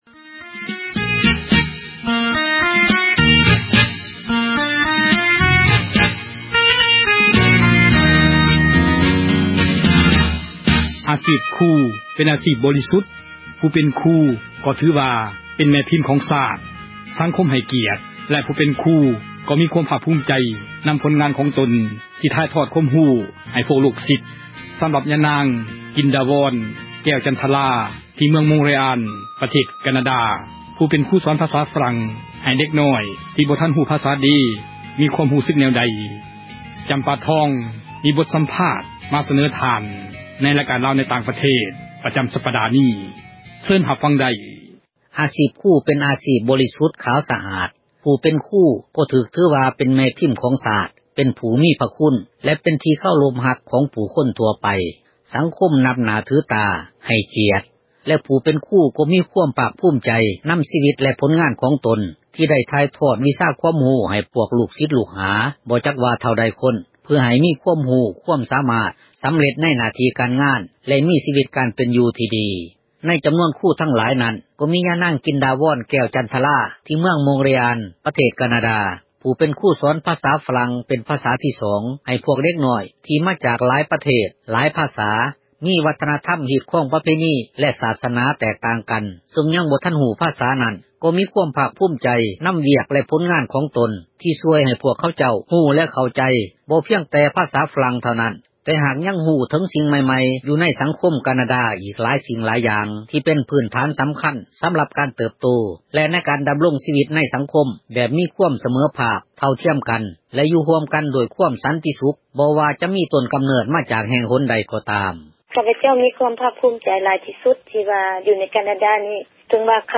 ສໍາພາດຄຣູສອນພາສາຝຣັ່ງ